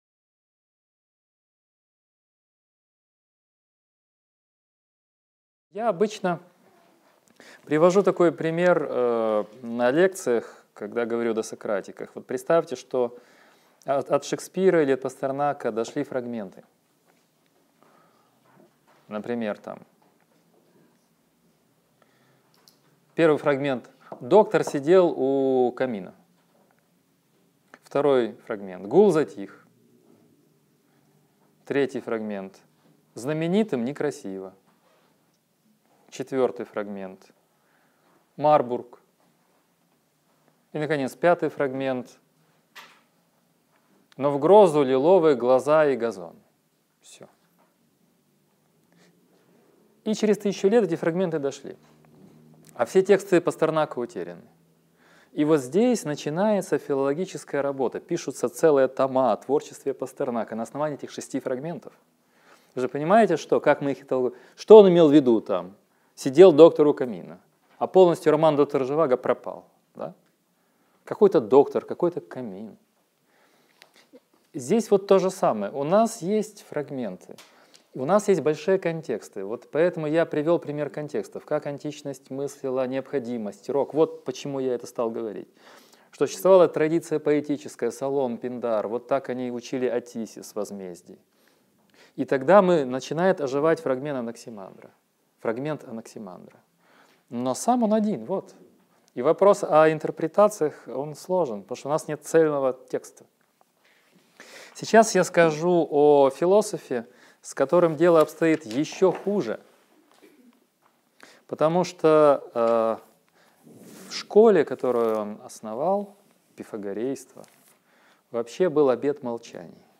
Аудиокнига Лекция 4. Пифагор и пифагорейство | Библиотека аудиокниг